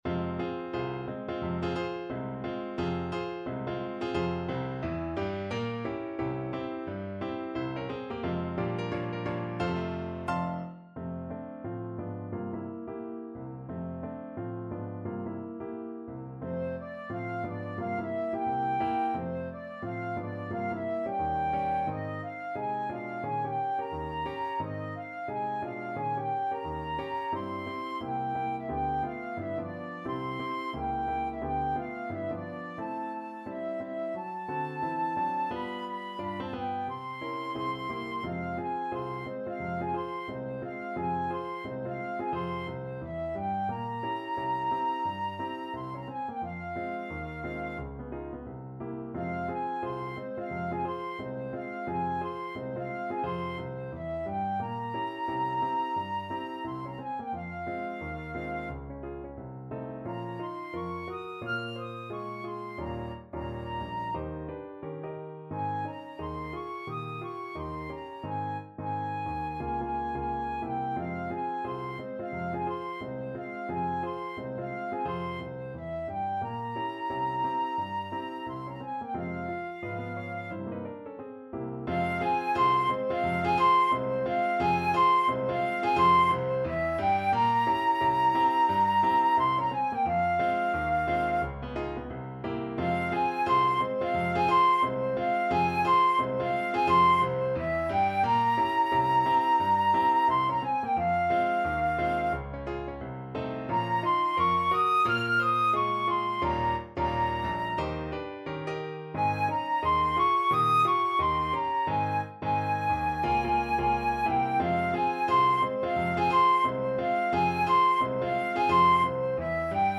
~ = 176 Moderato
Jazz (View more Jazz Flute Music)
Rock and pop (View more Rock and pop Flute Music)